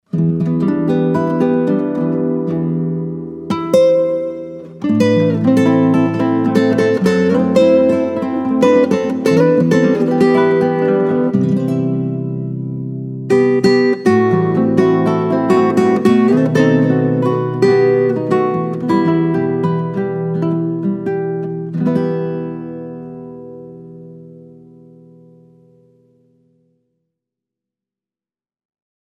Я недавно купил две гитары с нейлоном, одну классику и одну фламенко.
У меня было свободных пол часа и я попробовал записать Ваш кусочек на своих гитарах. вот что у меня получилось.